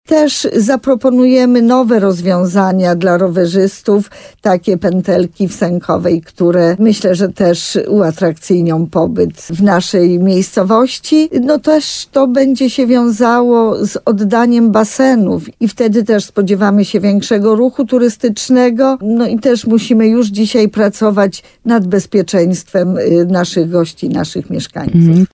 – Ścieżki zostaną też uzupełnione na istniejących trasach w kierunku Owczar i Wapiennego – mówi wójt gminy Sękowa, Małgorzata Małuch.